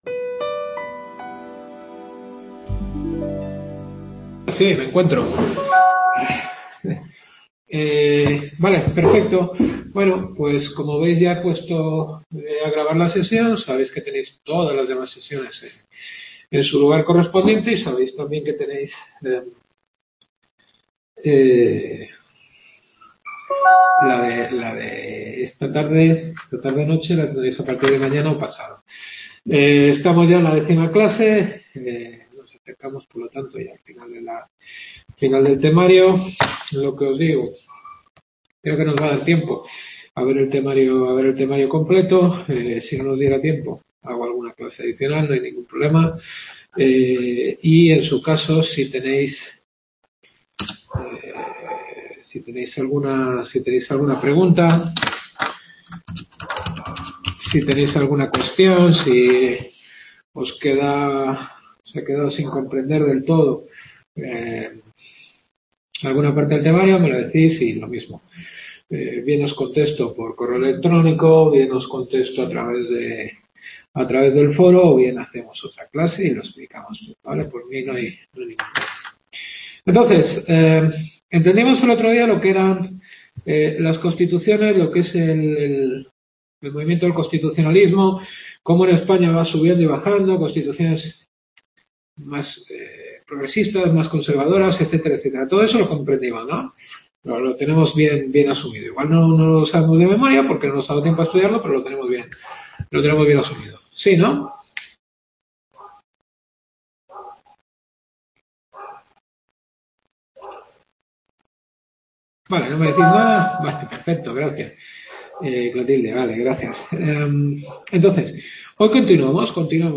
Décima clase.